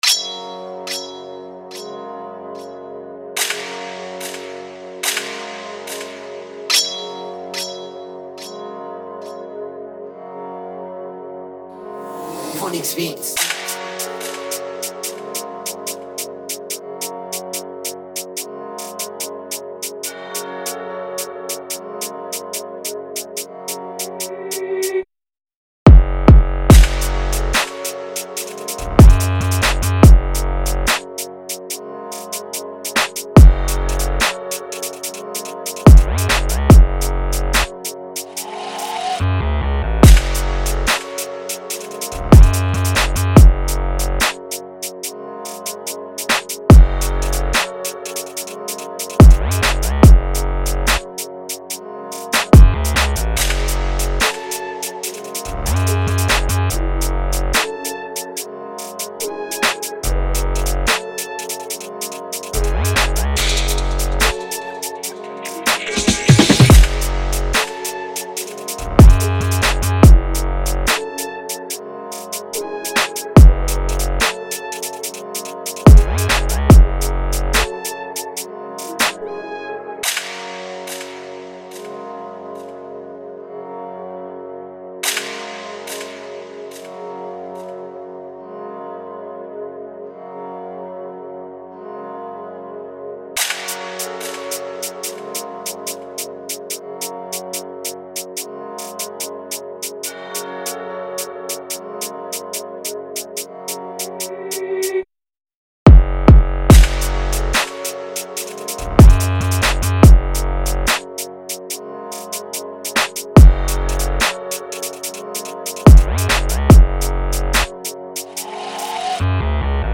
رپفارسی